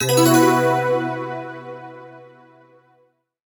開始音